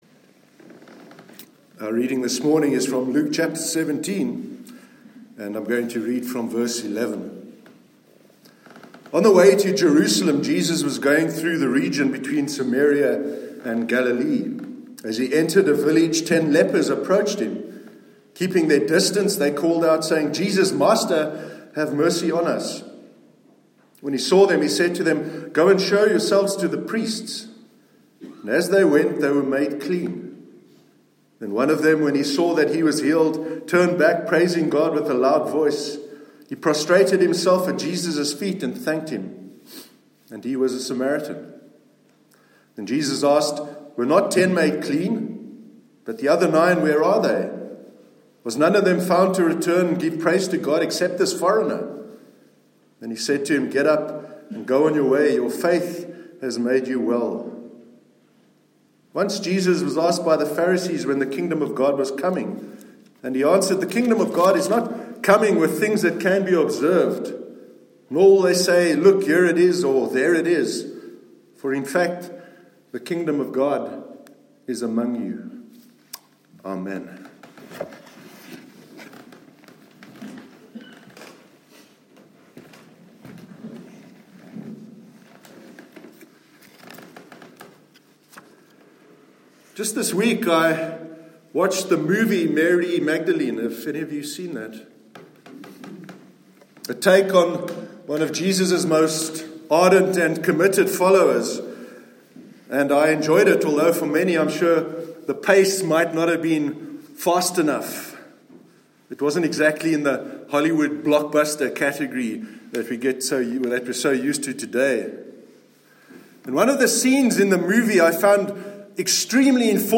Sermon on the Kingdom of God- 26th August 2018 – NEWHAVEN CHURCH